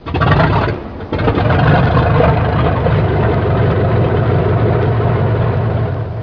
دانلود آهنگ طیاره 12 از افکت صوتی حمل و نقل
دانلود صدای طیاره 12 از ساعد نیوز با لینک مستقیم و کیفیت بالا
جلوه های صوتی